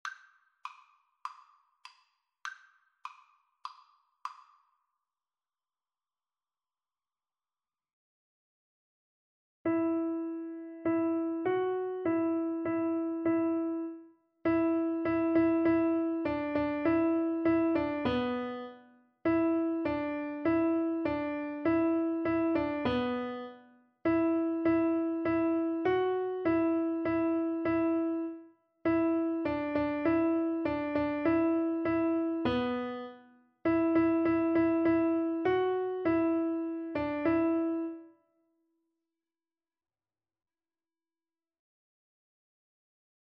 Free Sheet music for Piano Four Hands (Piano Duet)
Moderato
C major (Sounding Pitch) (View more C major Music for Piano Duet )